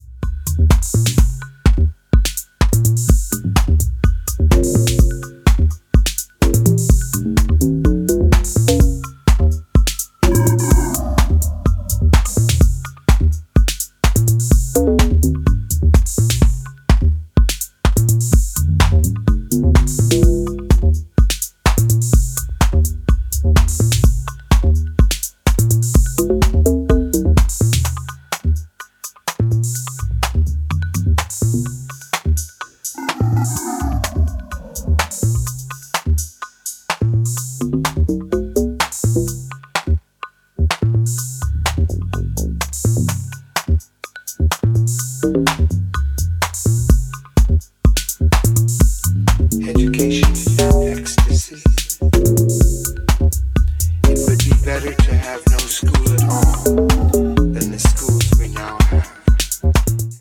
French duo